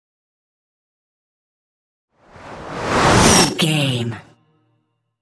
Trailer dramatic raiser short
Sound Effects
Atonal
futuristic
intense
tension
dramatic
riser